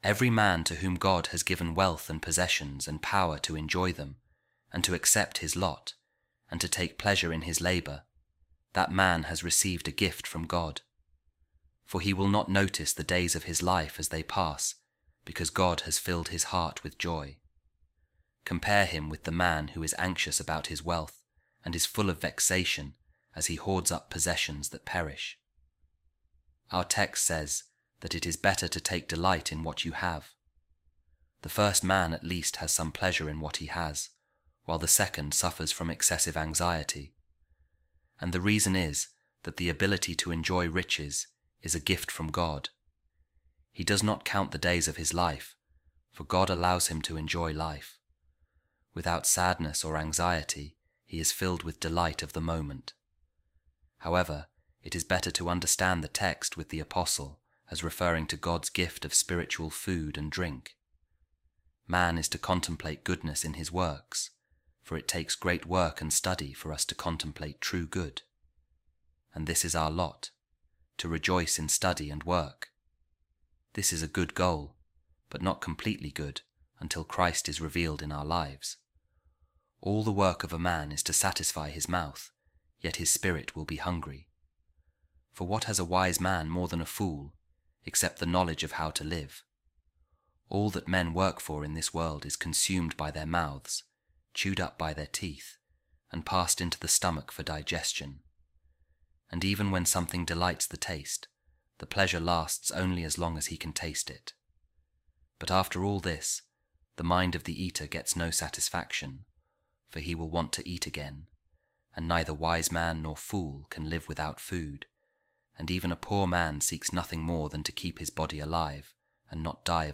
A Reading From The Commentary On Ecclesiastes By Saint Jerome | Seek The Things That Are Above